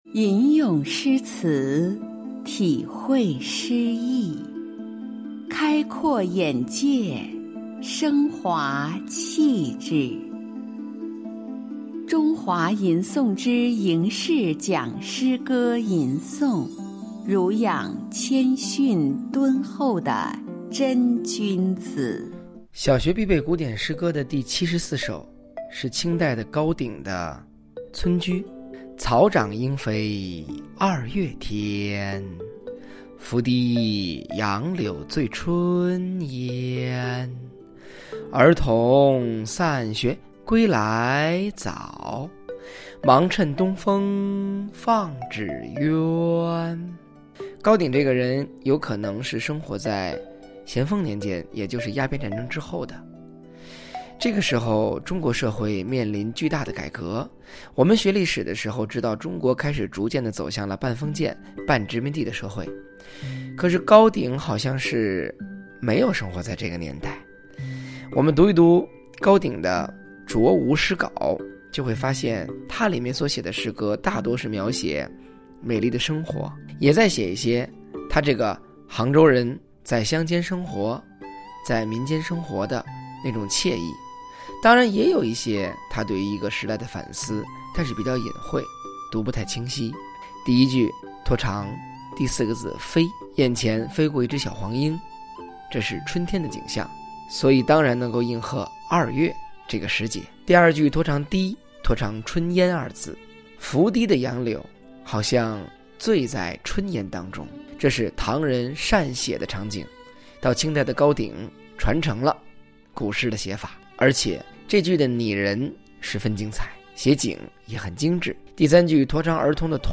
小74 吟诵书写【清】高鼎《村居》